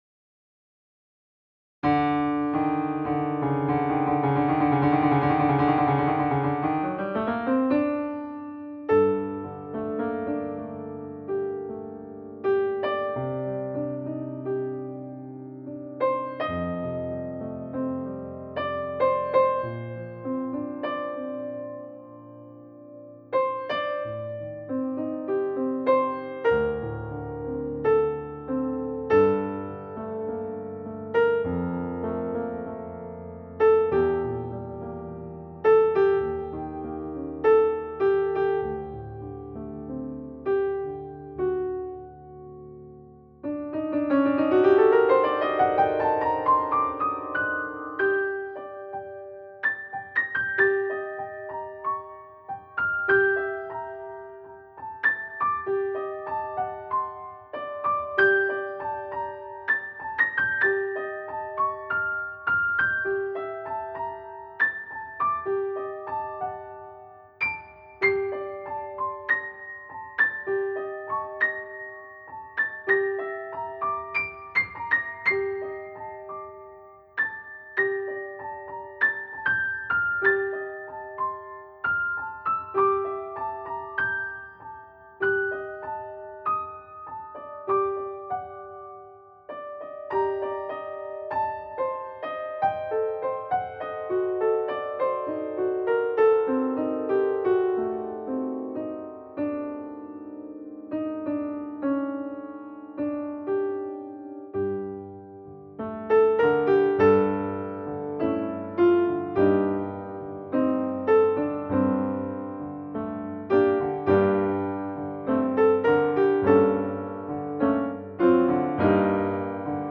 Wochenlied für den 14.06.2020